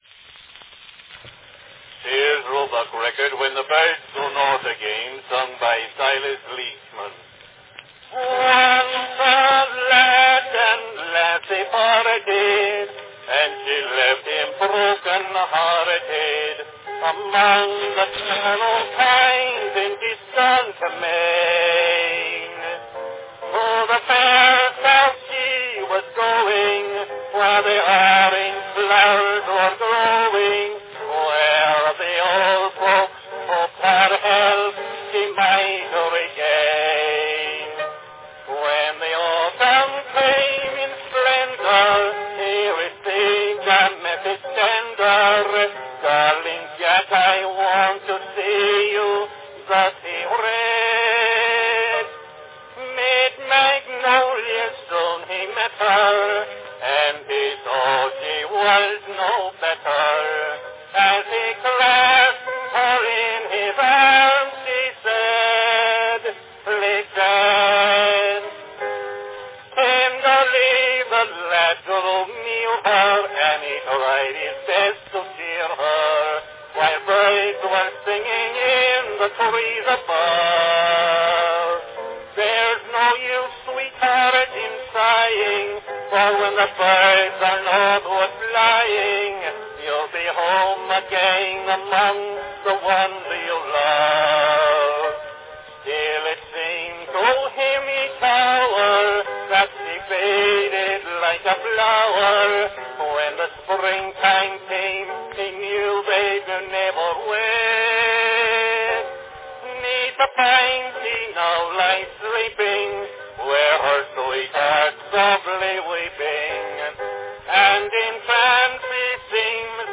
Category Song
He would play piano and sing into 3 or so phonographs at a time, song after song, day after day.
Here, we have a recording he made for Sears-Roebuck on a concert brown wax cylinder (5" diameter instead of the 2" diameter standard cylinders).   From the crisp, forward sound of his announcement we can tell this was an original record, not a copy.